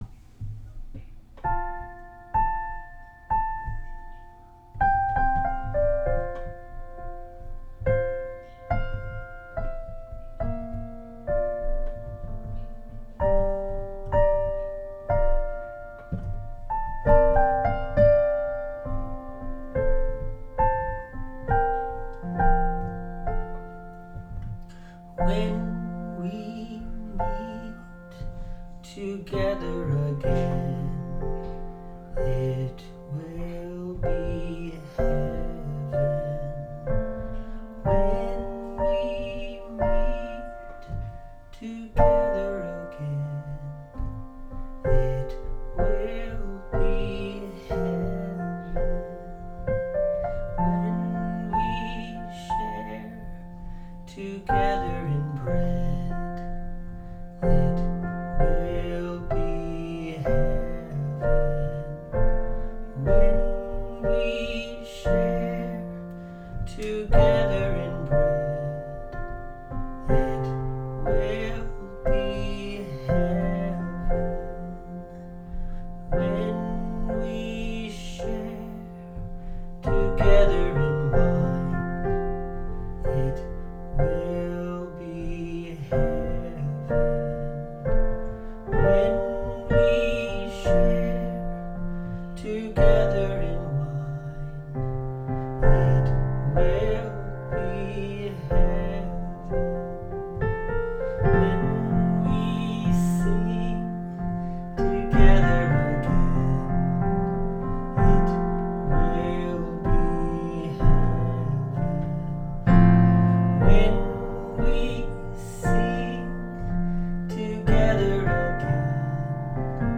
A song of hope that we will meet again.